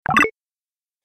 جلوه های صوتی
دانلود صدای کلیک 43 از ساعد نیوز با لینک مستقیم و کیفیت بالا